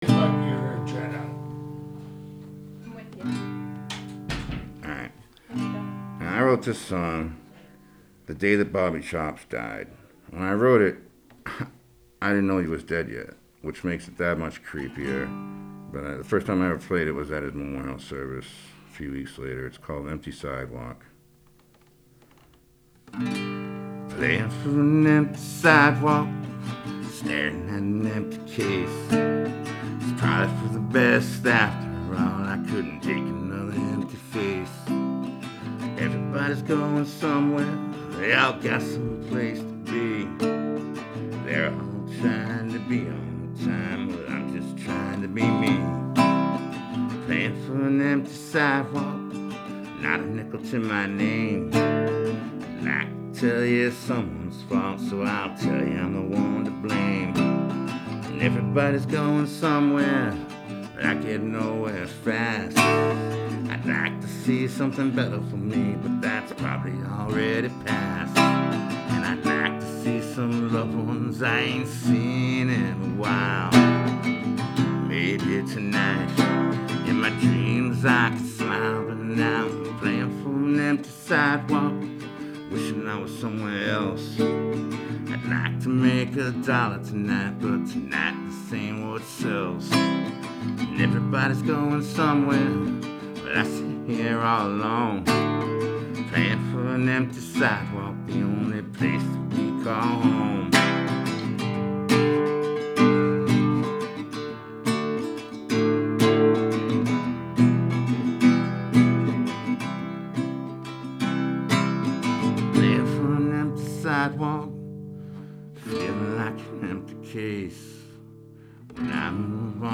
Music
Members of the homeless community recorded these songs in honor of the people who died this year.